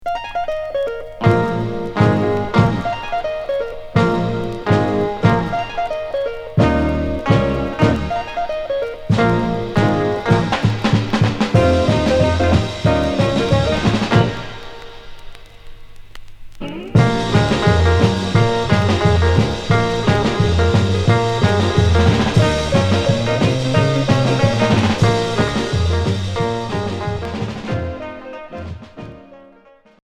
Twist